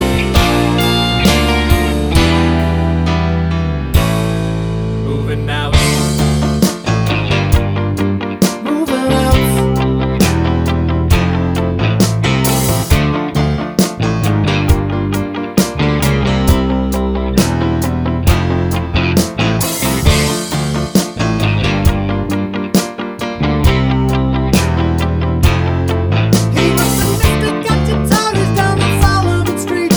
Without Saxophone Pop (1980s) 3:27 Buy £1.50